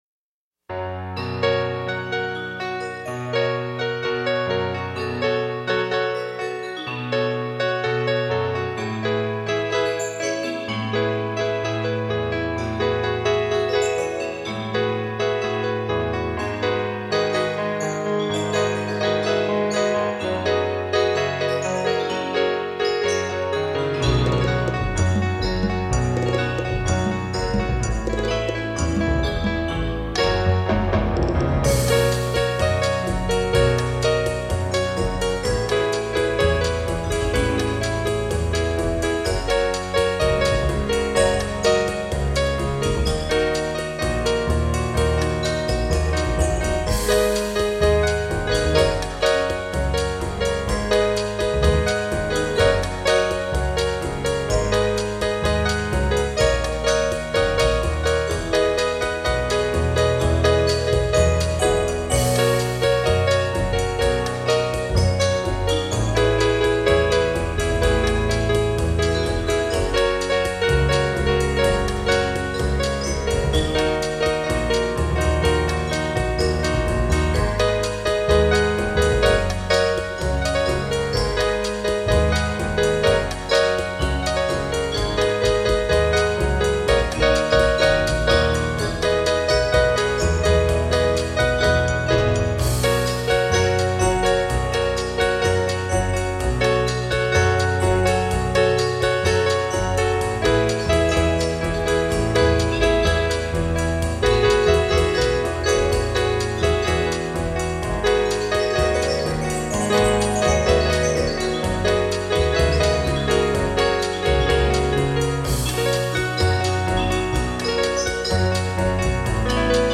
4:00 Samba